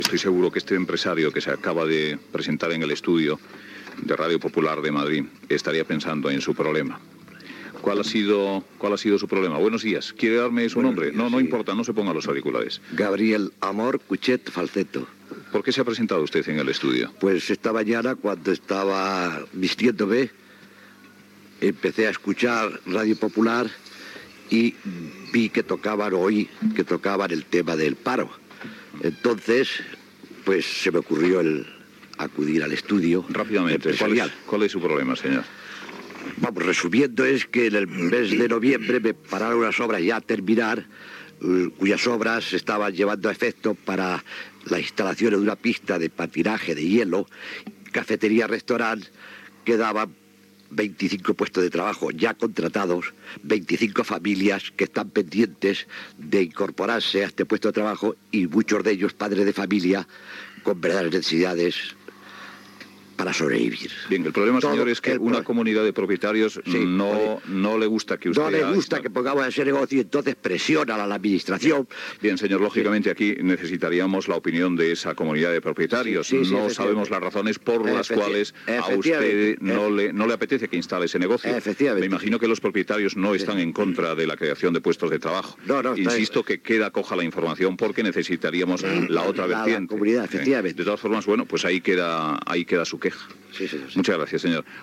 Testimoni d'un aturat.
Persones presents a l'estudi i trucades telefòniques.
Intervenció del ministre de Treball Joaquín Almunia.
Info-entreteniment